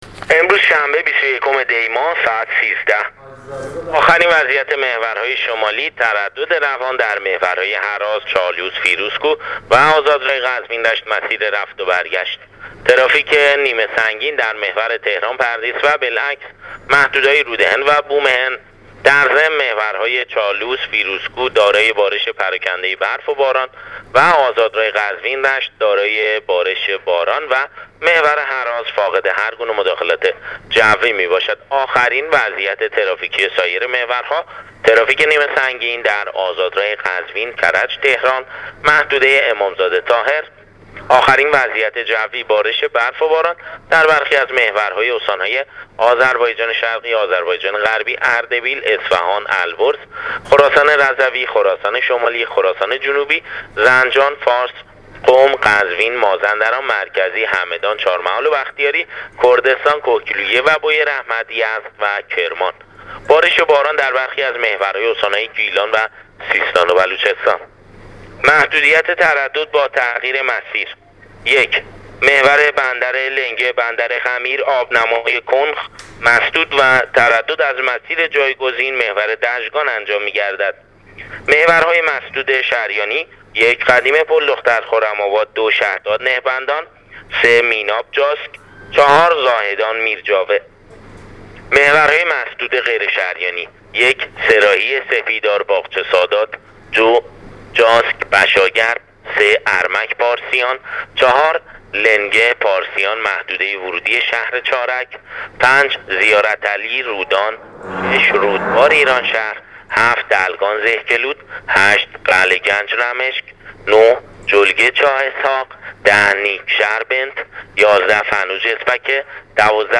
گزارش رادیواینترنتی از آخرین وضعیت‌ ترافیکی جاده‌ها تا ساعت۱۳ بیست‌ویکم دی ۱۳۹۸